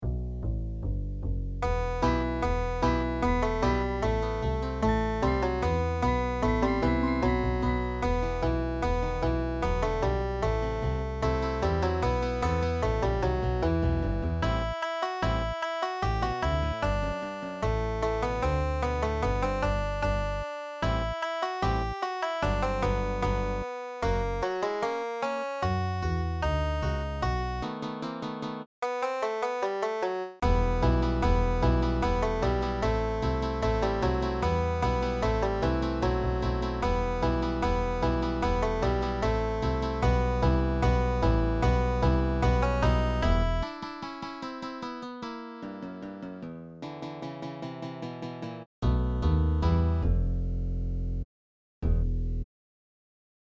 Newest 100 4-String (Tenor/Plectrum) Songs banjo songs which Banjo Hangout members have uploaded to the website.